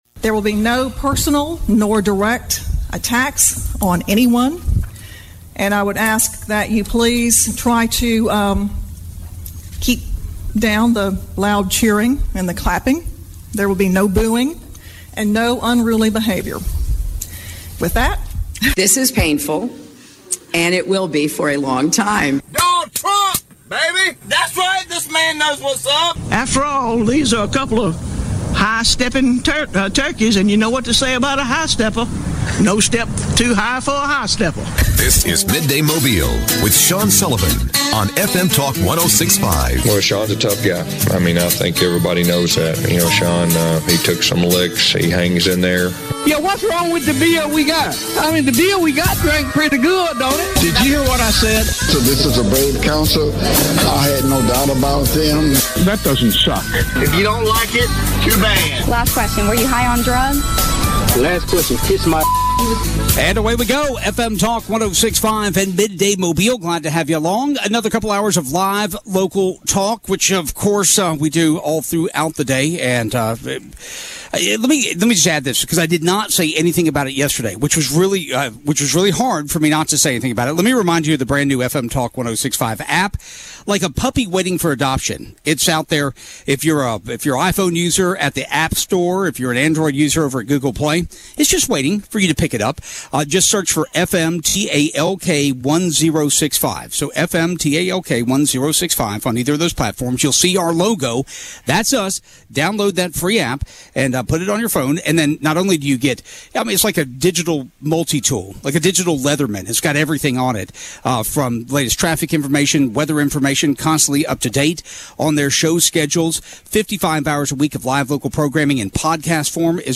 broadcasts live from the Greater Gulf State Fair